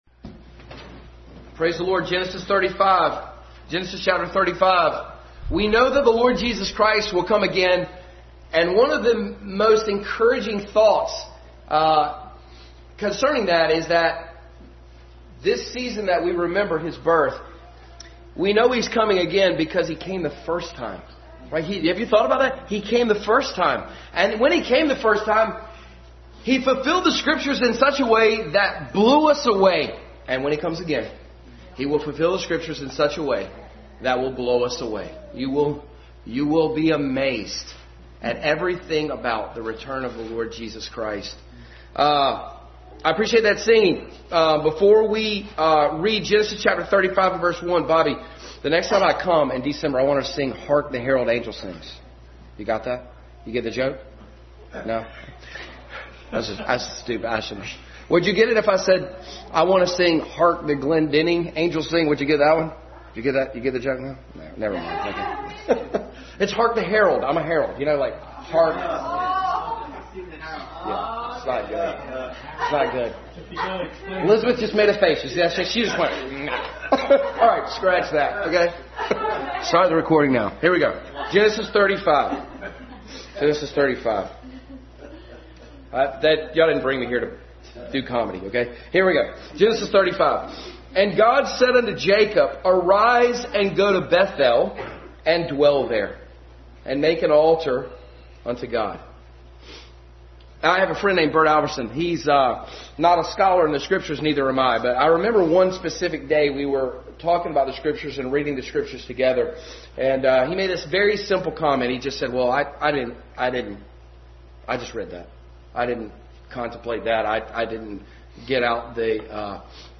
Go to Bethel Passage: Genesis 35:1, 12:1-10, 28:11-18, Mark 2:14 Service Type: Family Bible Hour